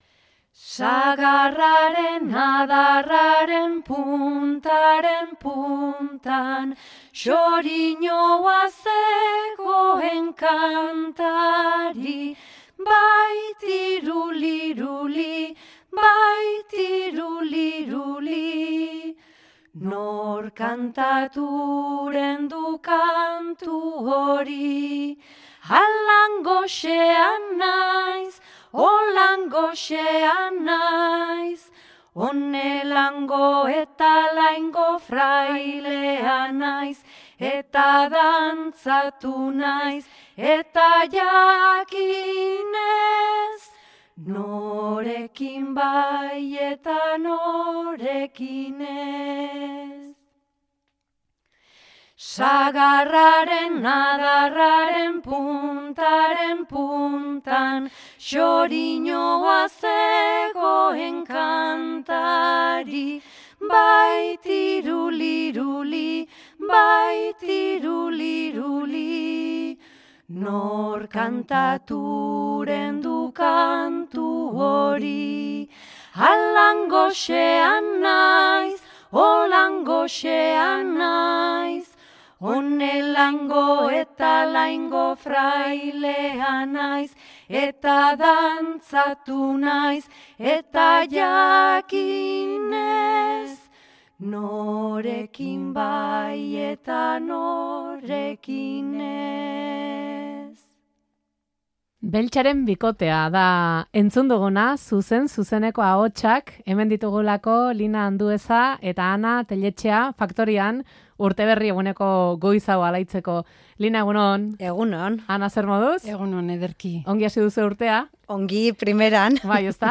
kantuan zuzenean urteberri egunean
elkarrekin abesten dute bikote modura.